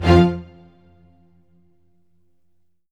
ORCHHIT F3-L.wav